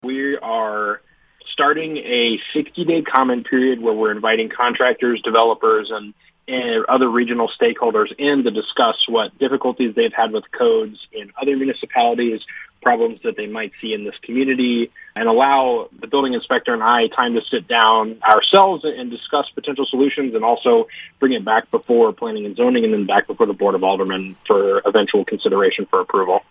Jackson states the city has opened a 60-day comment period to seek input regarding the code and any challenges present in Knob Noster.